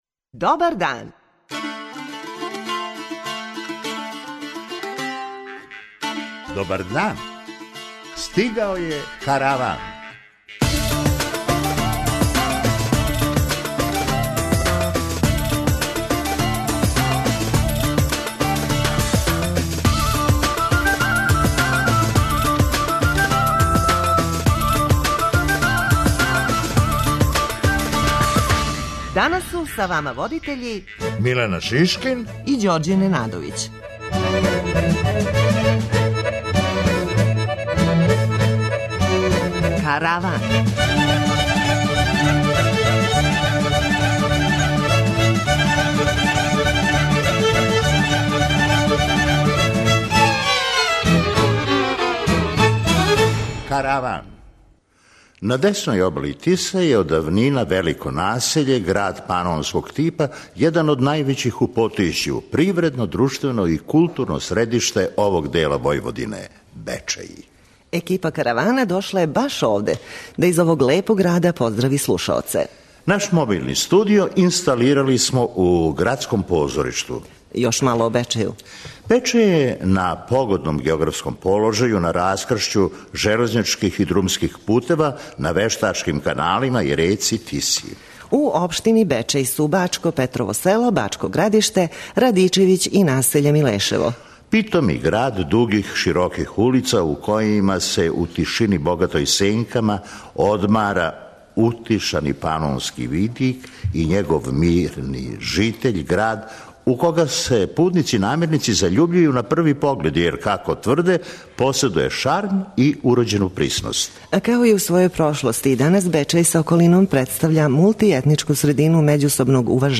На десној обали Тисе је од давнина велико насеље, град панонског типа, један од највећих у Потисју, привредно, друштвено и културно средиште овог дела Војводине - Бечеј. Екипа Каравана дошла је баш овде, да из овог лепог града поздрави слушаоце.
преузми : 23.05 MB Караван Autor: Забавна редакција Радио Бeограда 1 Караван се креће ка својој дестинацији већ више од 50 година, увек добро натоварен актуелним хумором и изворним народним песмама.